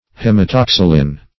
Hematoxylin \Hem`a*tox"y*lin\, n.
hematoxylin.mp3